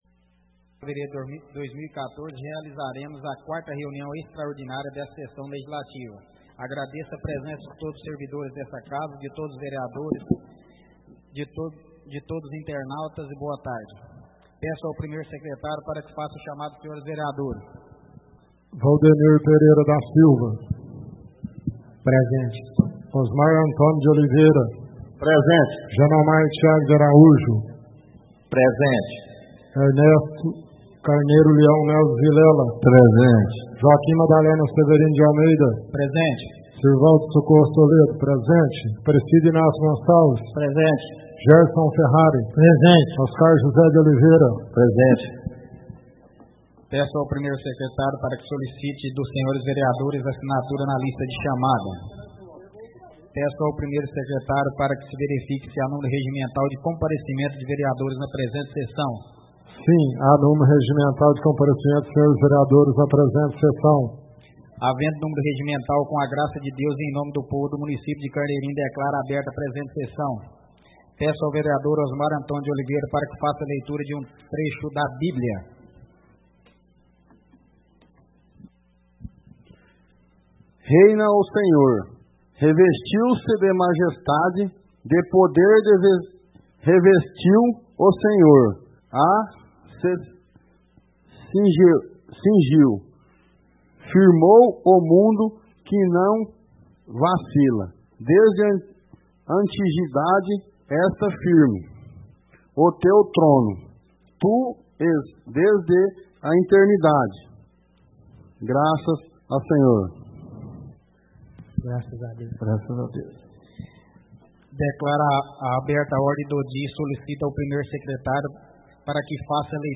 Áudio da 4ª (quarta) sessão extraordinária de 2014, realizada no dia 24 de Fevereiro de 2014, na sala de sessões da Câmara Municipal de Carneirinho, Estado de Minas Gerais.